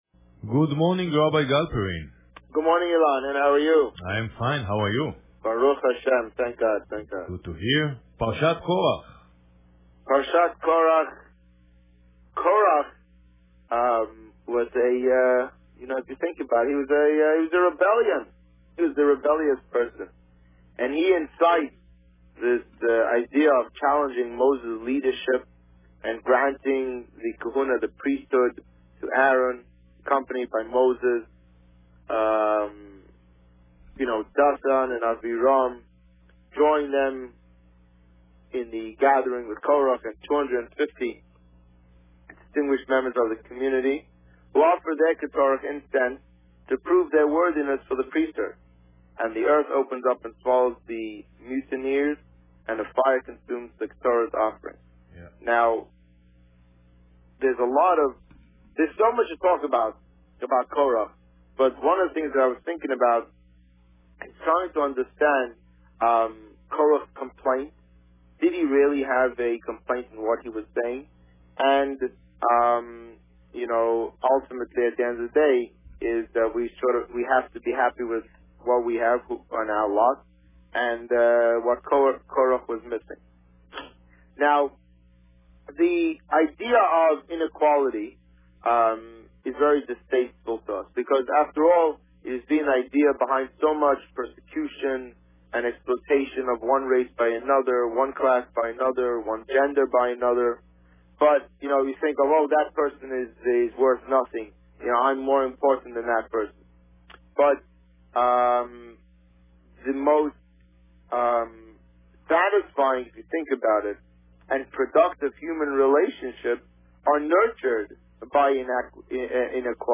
On June 6, 2013, the Rabbi spoke about Parsha Korach. Listen to the interview here.